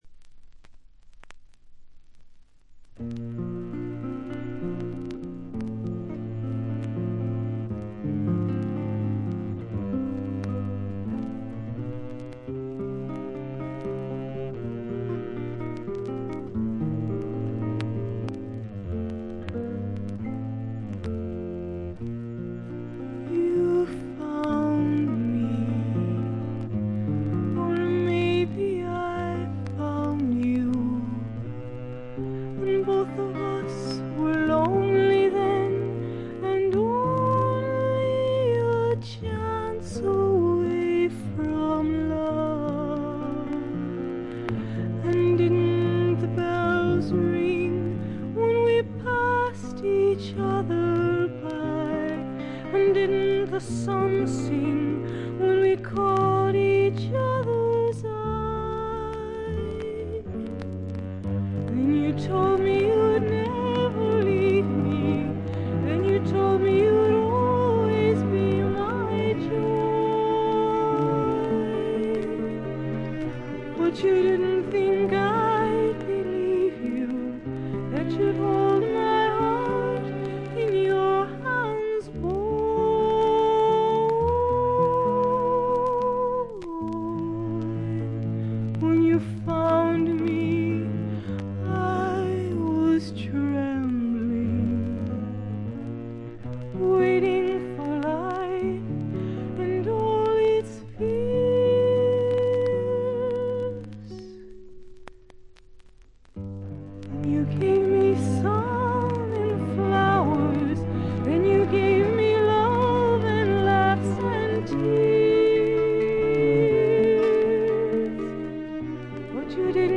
プレスがいまいちなのか、見た目よりバックグラウンドノイズやチリプチは多め大きめ。凶悪なものや周回ノイズはありません。
それを支えるシンプルなバックも見事！の一言。
試聴曲は現品からの取り込み音源です。